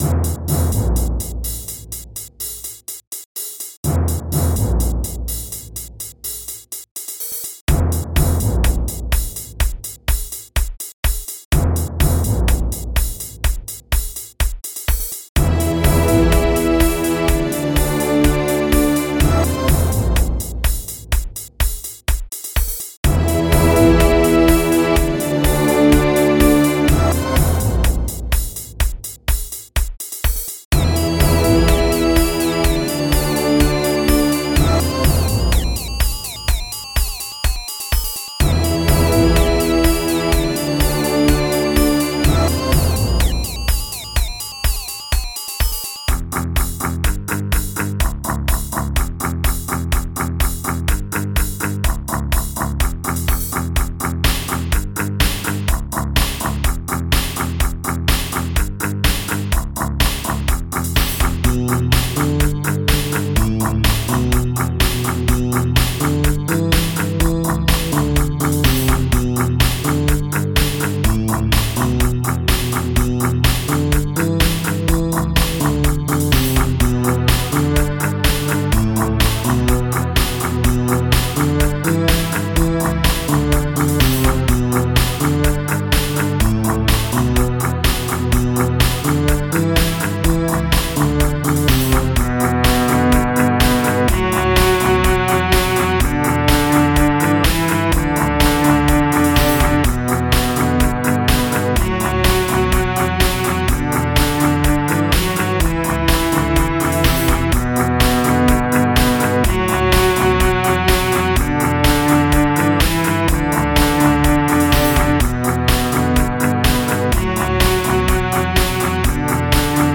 Timpani
Rc High Hat Closed
Bass Drum
Full Strings
Orchestra Strings
Distorted Guitar Chunk
TV Snare Drum
Dream Guitar
Violin